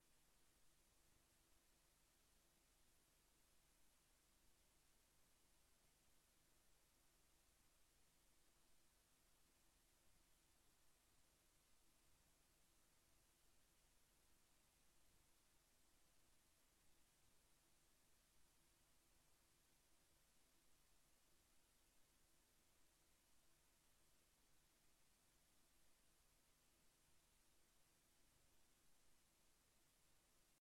Raadzaal